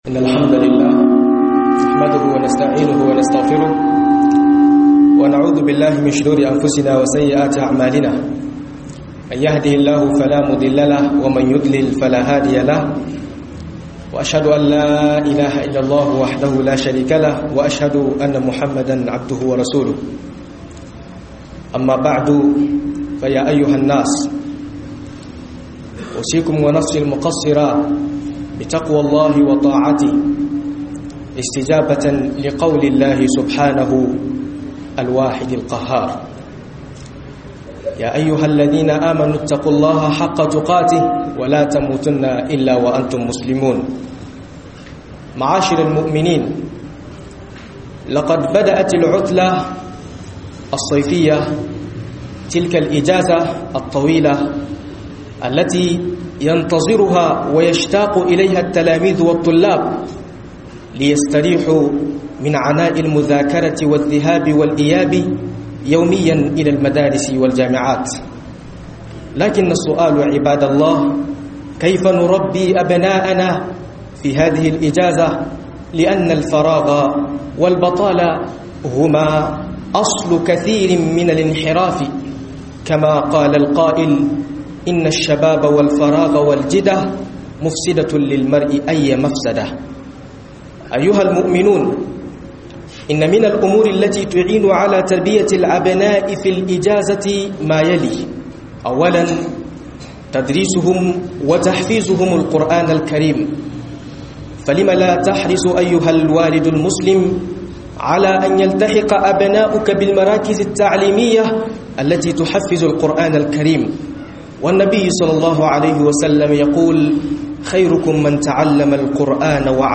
003-Tarbiyyar yara a vacance - HUDUBOBI